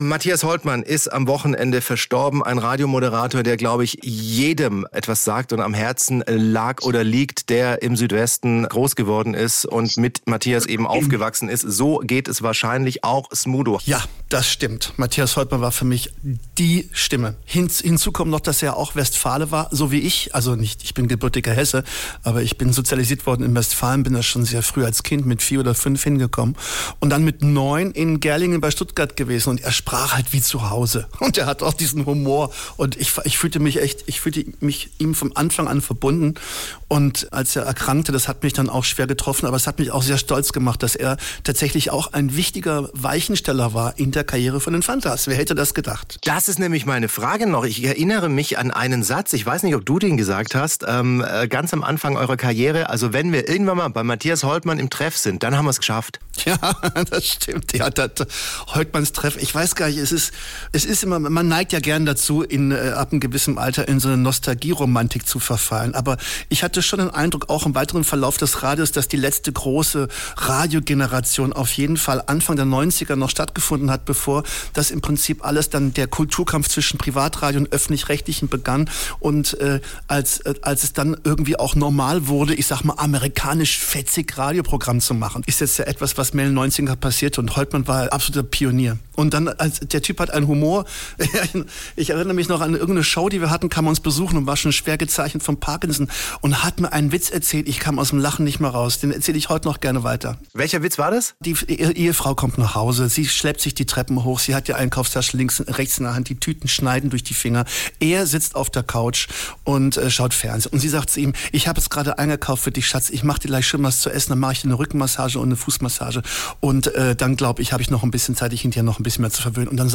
"Es hat mich stolz gemacht", sagt Smudo von den Fantastischen Vier im SWR1 Interview, "dass Holtmann ein wichtiger Weichensteller in der Karriere der Fantas war".